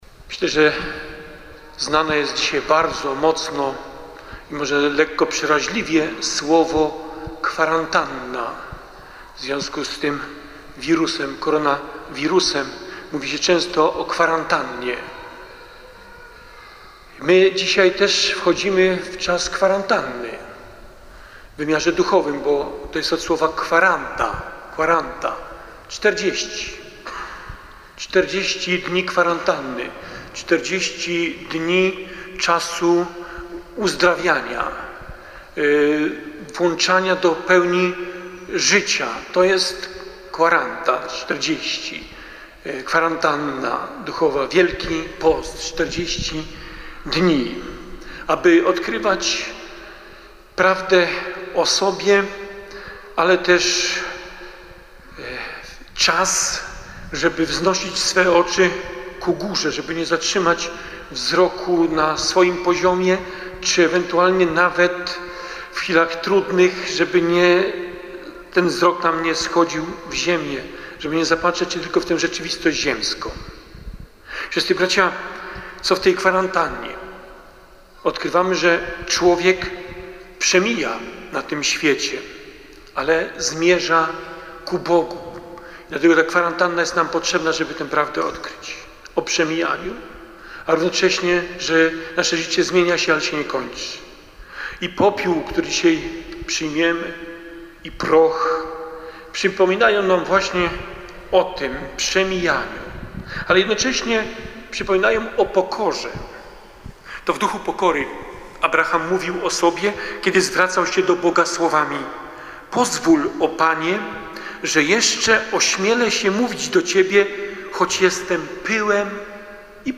Mszy św. sprawowanej w Środę Popielcową w koszalińskiej katedrze przewodniczył bp Krzysztof Włodarczyk.
homilia bp Włodarczyk Środa Popielcowa.MP3